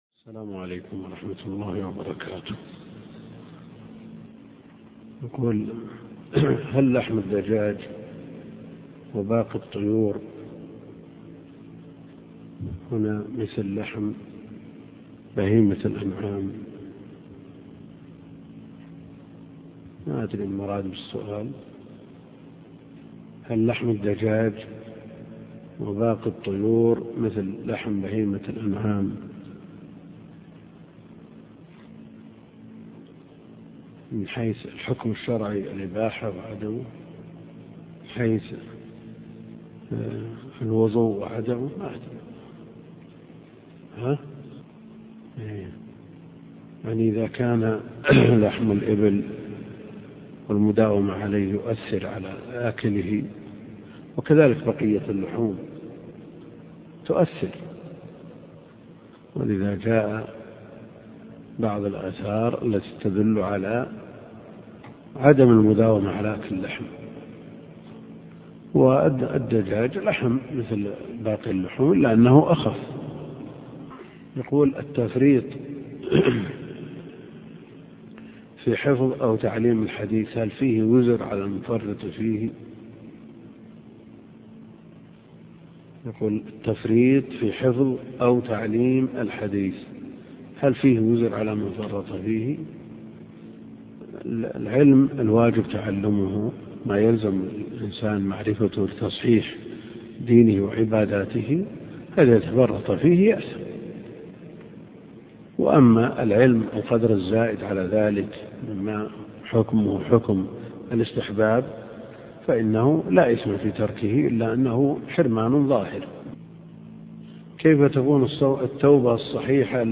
عنوان المادة الدرس (14) شرح سنن ابن ماجه تاريخ التحميل الأثنين 27 فبراير 2023 مـ حجم المادة 29.99 ميجا بايت عدد الزيارات 347 زيارة عدد مرات الحفظ 135 مرة إستماع المادة حفظ المادة اضف تعليقك أرسل لصديق